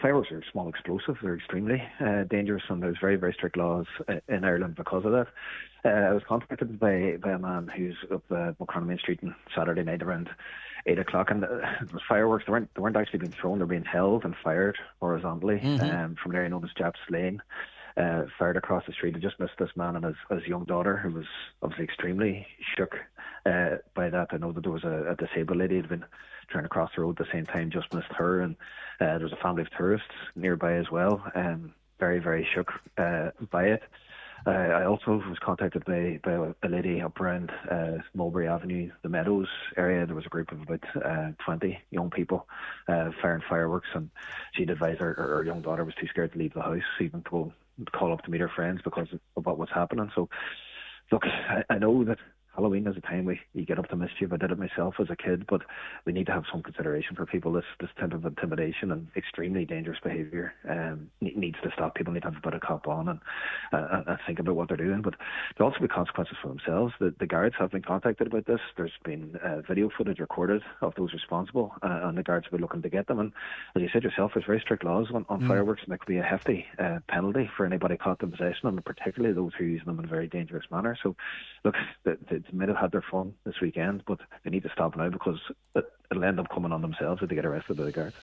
Councillor Jack Murray, on today’s Nine til Noon Show, confirmed that video footage of the incident has been passed on to Gardai: